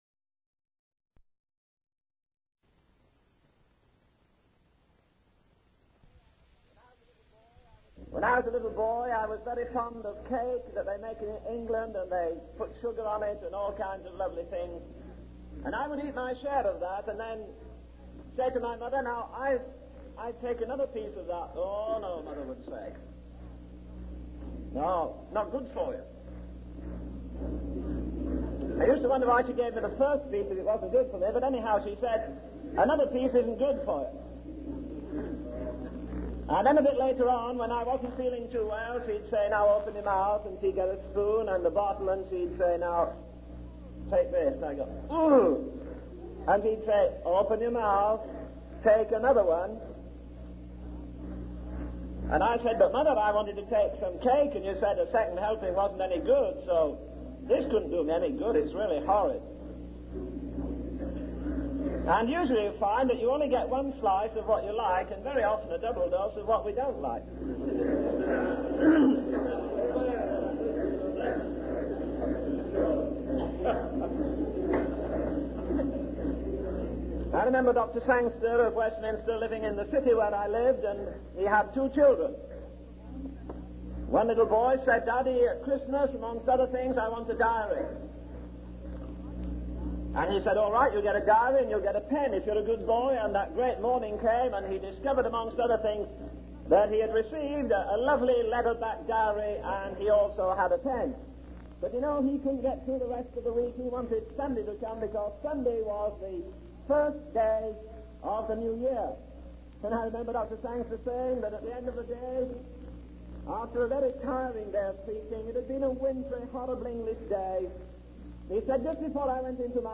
In this sermon, the preacher discusses the concept of worldliness and its impact on society.